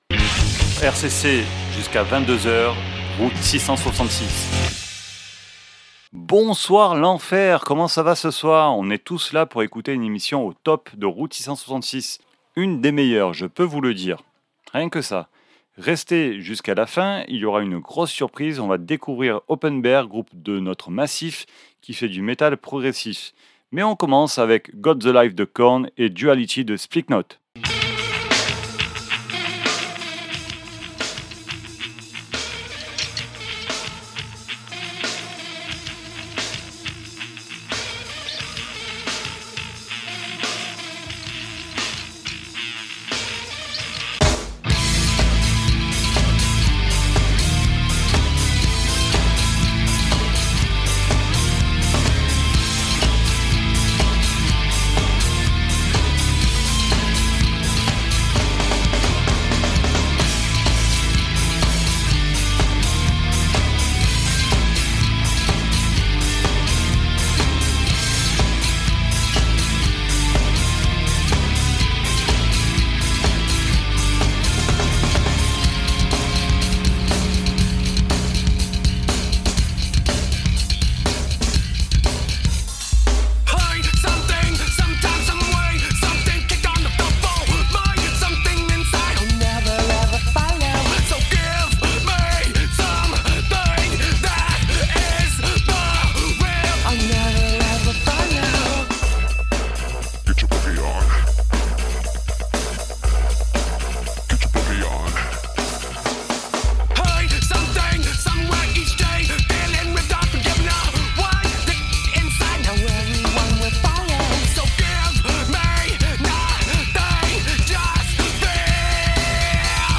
Route 666 Emission du mercredi 10 DECEMBRE 2025 votre dose de hard rock métal sur RCC !